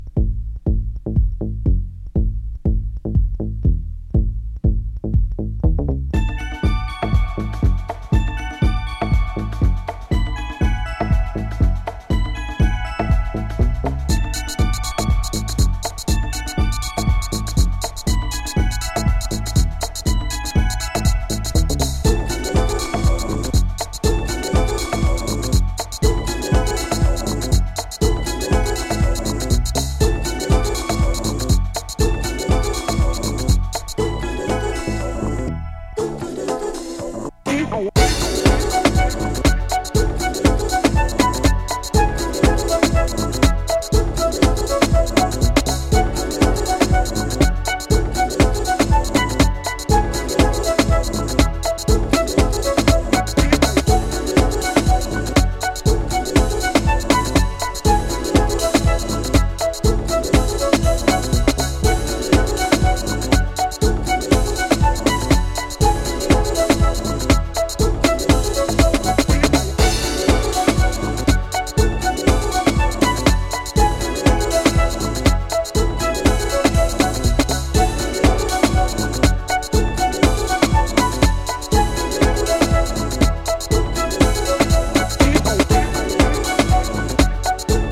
Japanese House
deep House & dance music